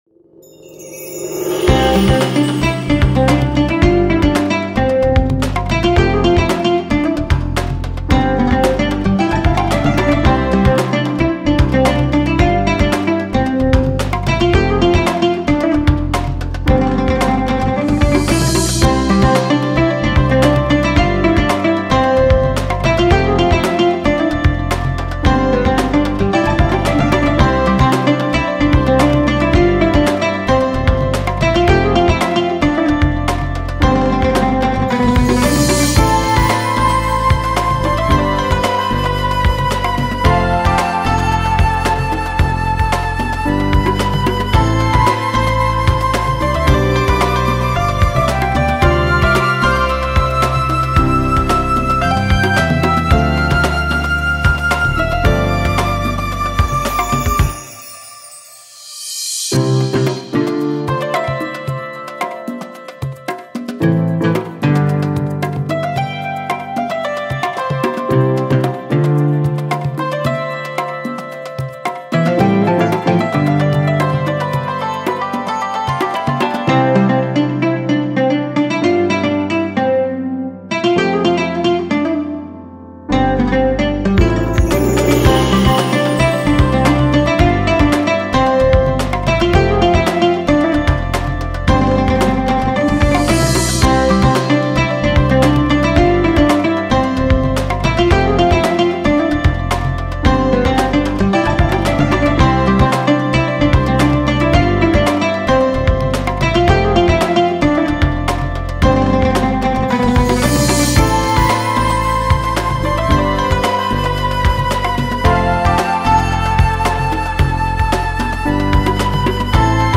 ramadan-bg-music.mp3